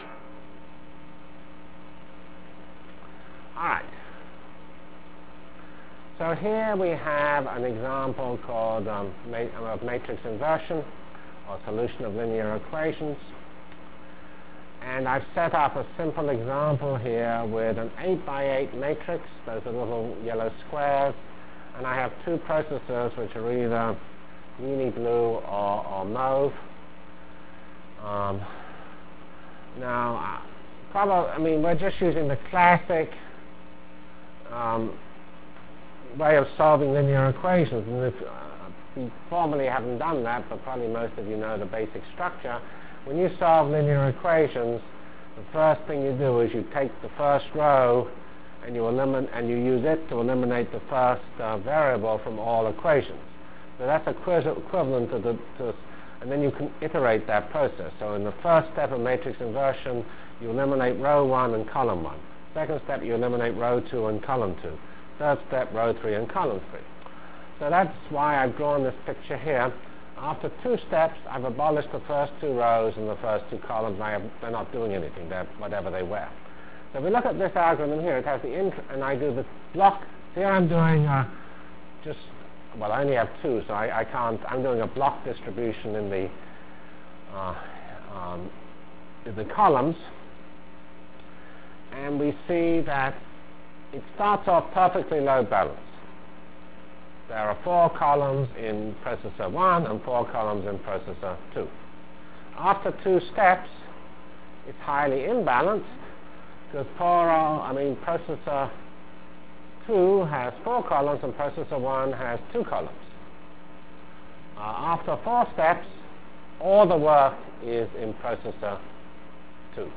From CPS615-Align and Distribute in HPF Delivered Lectures of CPS615 Basic Simulation Track for Computational Science -- 1 October 96. by Geoffrey C. Fox *